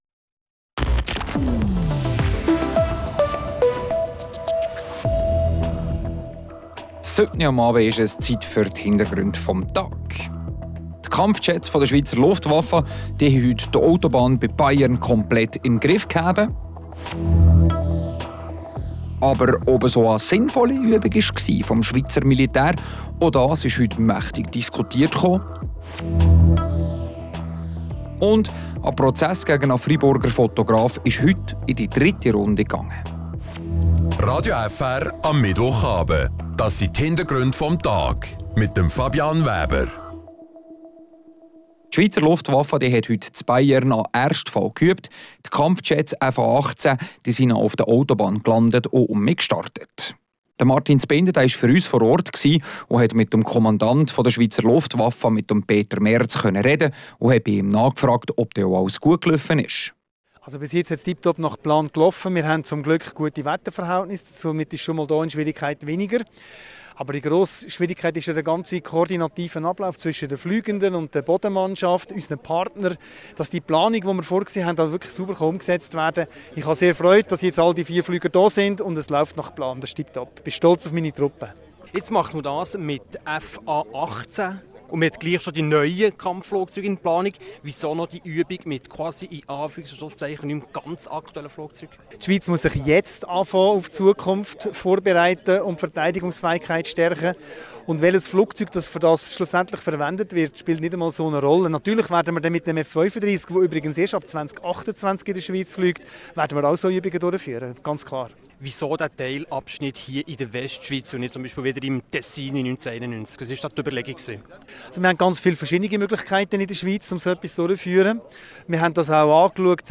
Wir sprechen mit dem Kommandanten der Luftwaffe, Peter Merz, über die Übung mit den F/A18 Kampfjets und stellen kritische Fragen, ob diese Übung auch wirklich nötig war. Zudem schauen wir auf einen Prozess des Kantonsgerichts Freiburg gegen einen Freiburger Hobby-Fotografen.